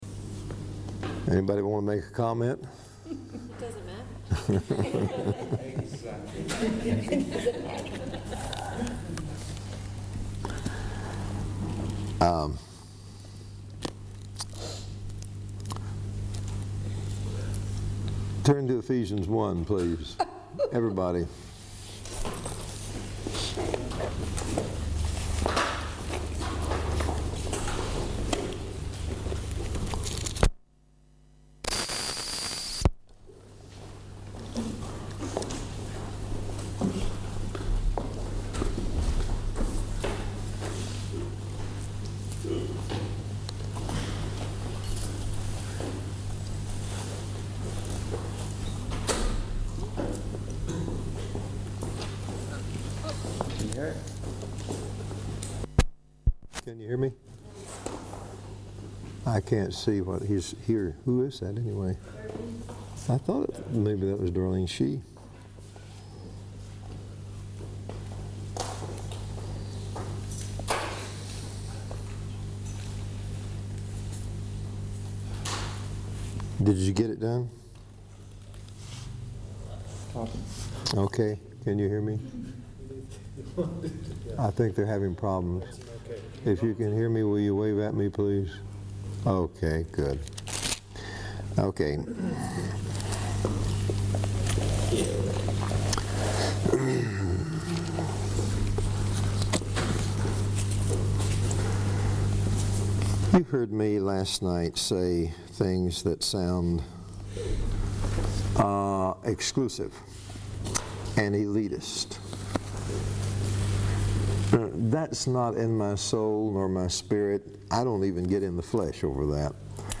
Final thoughts – Swiss Conference July 1997 Message #6 Download Transcription Play Audio